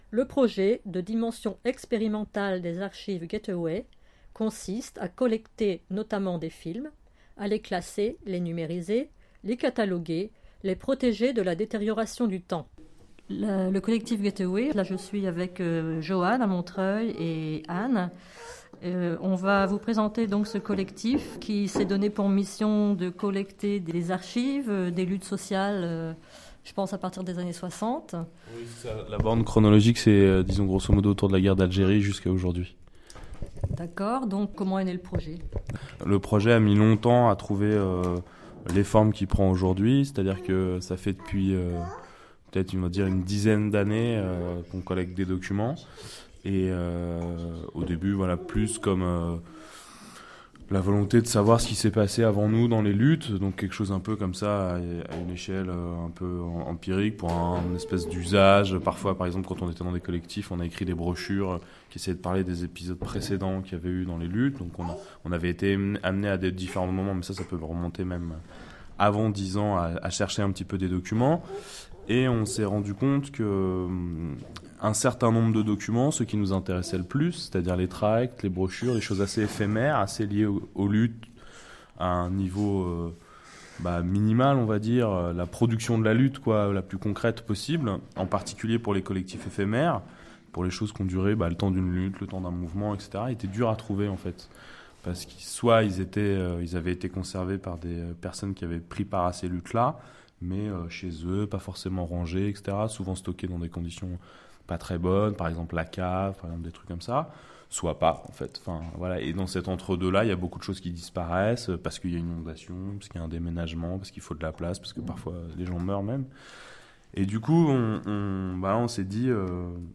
Suite à ces rencontres, une interview de deux membres des Archives Getaway a été enregistrée et diffusée le 5 janvier 2014 dans le cadre de l’émission Un peu d’air frais sur Radio libertaire. On pourra y trouver une présentation et des réflexions sur différentes dimensions du projet des Archives Getaway, et plus généralement autour des traces laissées par les luttes, le fait des les recueillir, et la transmission d’une histoire collective.